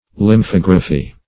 Lymphography \Lym*phog"ra*phy\, n. [Lymph + -graphy.]